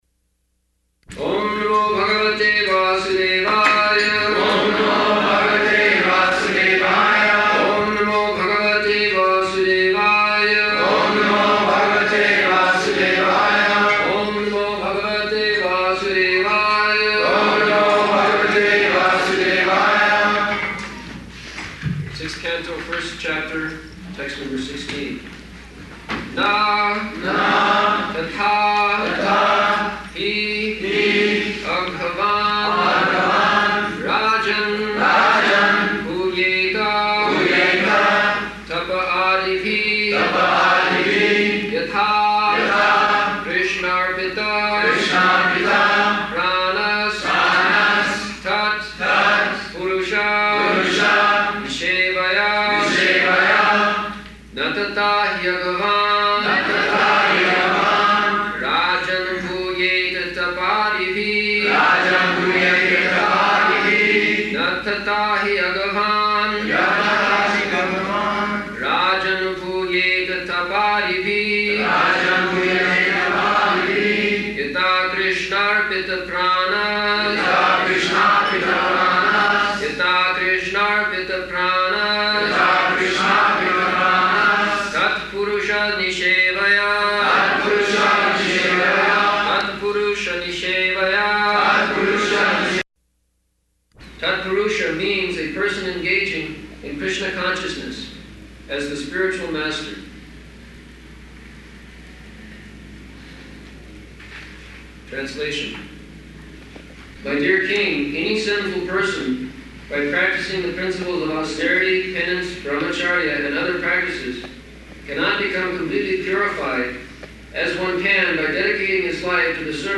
June 29th 1975 Location: Denver Audio file